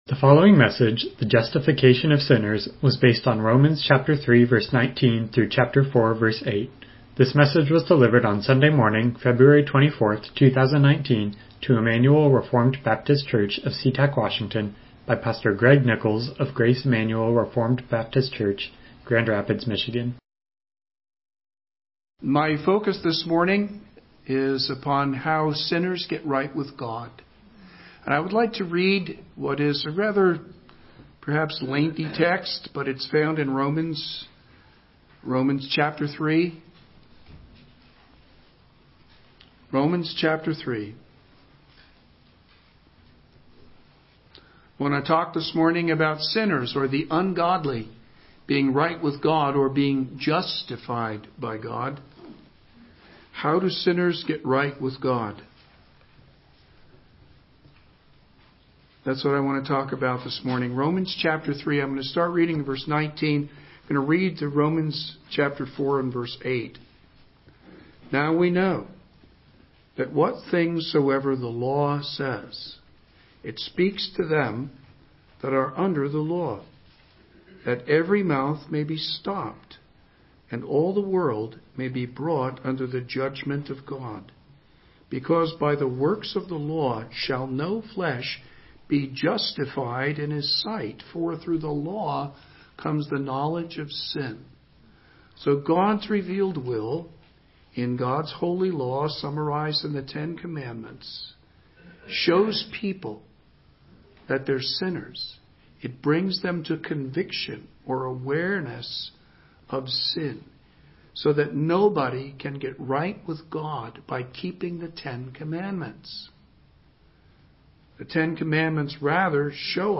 Miscellaneous Passage: Romans 3:19-4:8 Service Type: Morning Worship « God’s Righteousness